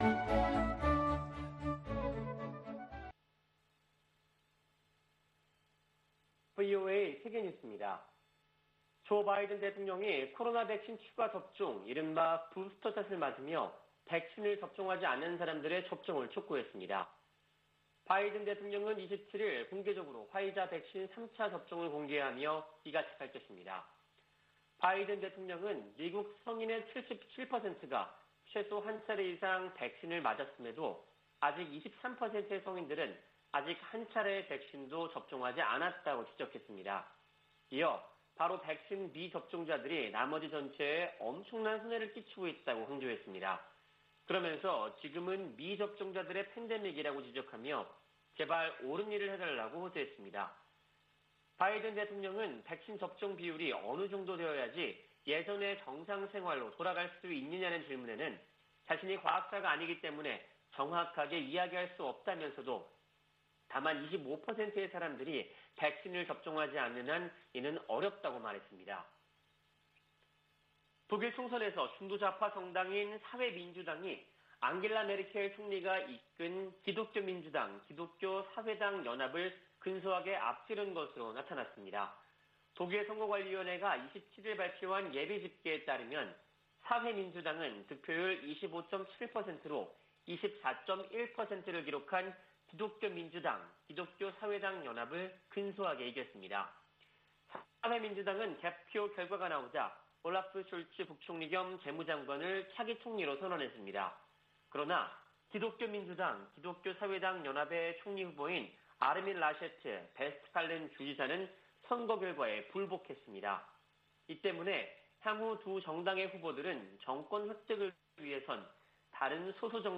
VOA 한국어 아침 뉴스 프로그램 '워싱턴 뉴스 광장' 2021년 9월 28일 방송입니다. 김여정 북한 노동당 중앙위원회 제1부부장이 연이틀 담화를 통해 유화적 메시지를 보내 주목됩니다. 북한은 제재 완화든 경제 발전이든 원하는 것이 있다면 협상장으로 나와야 한다고 미 국무부 부차관보가 지적했습니다. 국제원자력기구(IAEA)가 북한에 비핵화 이행을 촉구하고 국제사회의 외교적 노력을 지지하는 결의안을 채택했습니다.